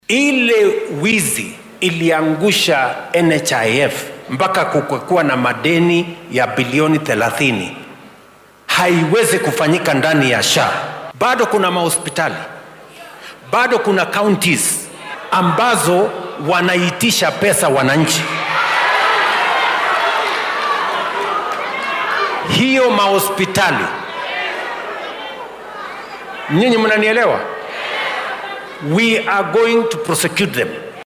Xilli uu madaxda deegaannada ismaamulka Kiambu kula hadlayay madaxtooyada State House ee magaalada Nairobi ayuu hoggaamiyaha qaranka hoosta ka xarriiqay in ay fal dambiyeed tahay in la dhaco lacago loogu talagalay bukaannada.